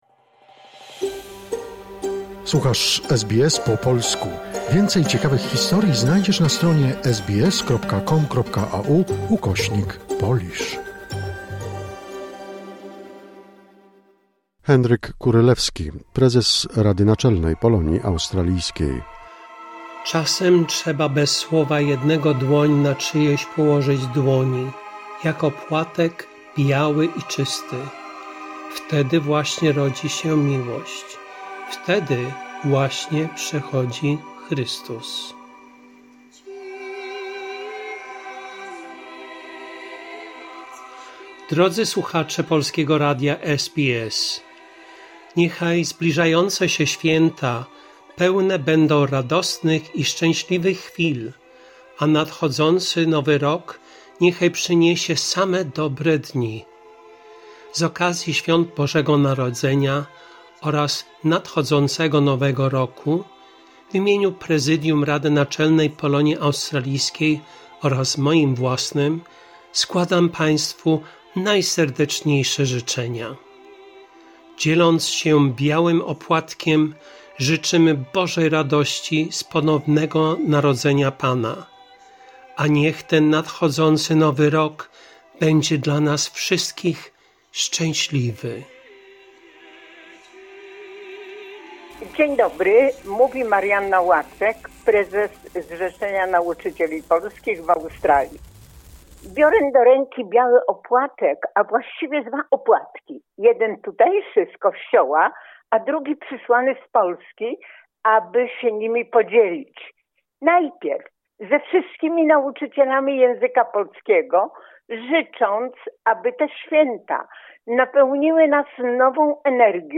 Życzenia świąteczne składają Polonii w Australii działacze społeczni, przedstawiciele organizacji polonijnych oraz Konsul Generalny RP w Sydney.